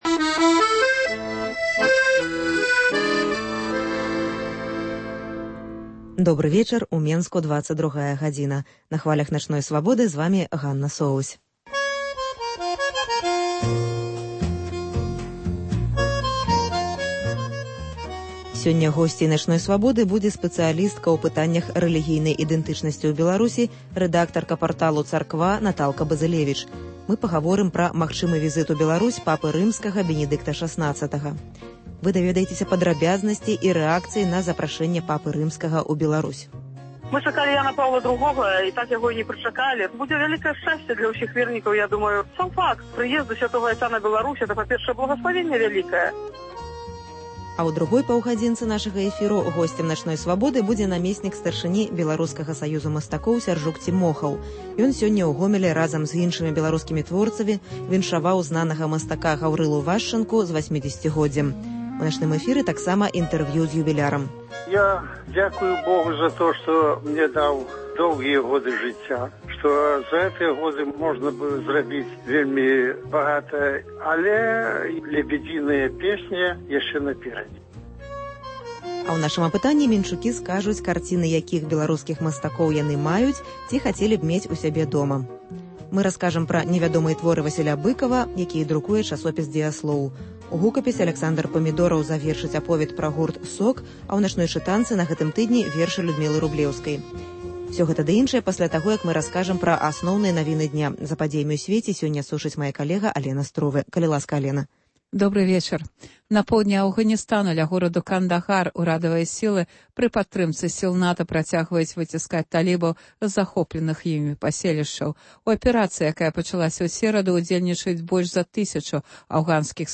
* Апытаньне: менчукі скажуць, карціны якіх беларускіх мастакоў яны маюць ці хацелі б мець у сябе дома. * Падрабязнасьці і рэакцыі на запрашэньне Папы Рымскага ў Беларусь.